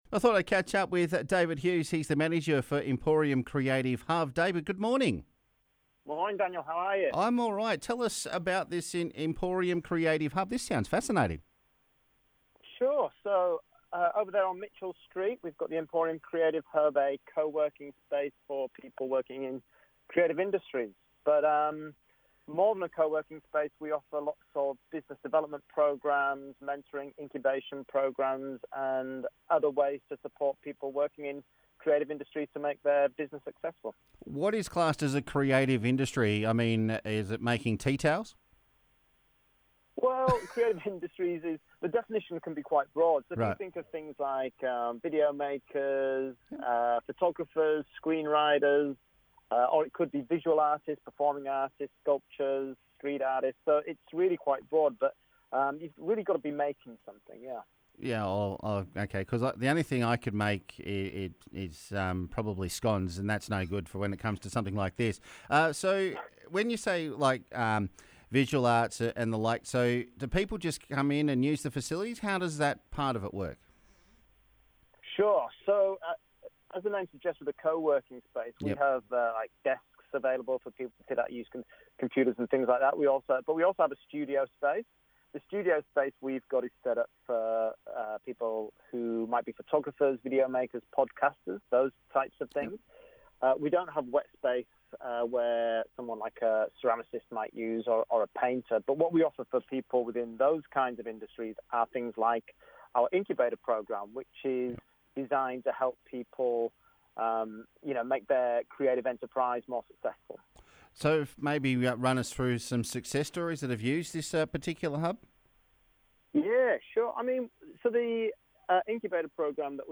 joined for a chat about what the hub is about and how you can apply.